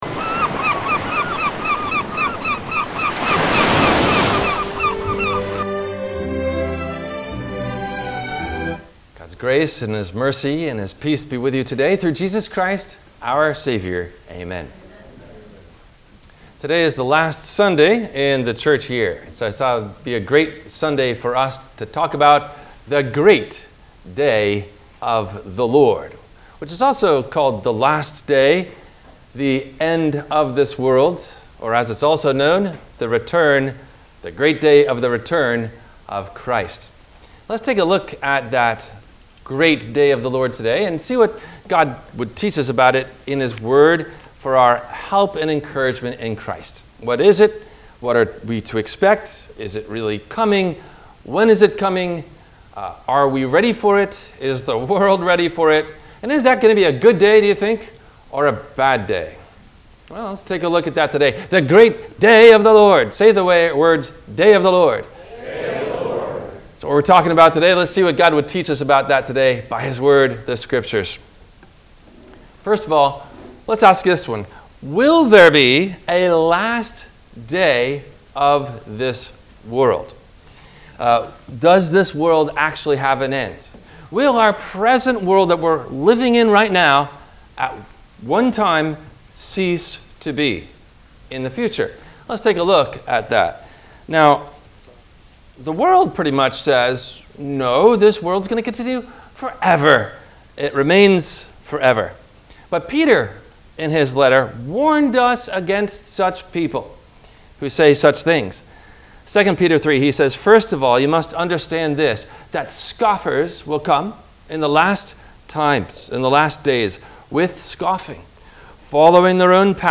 No Questions asked before the Sermon message: